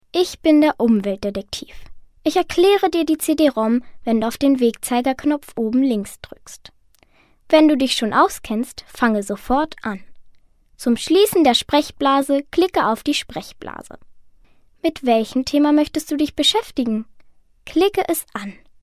Werbesprecherin